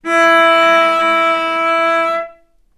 cello / sul-ponticello
vc_sp-E4-ff.AIF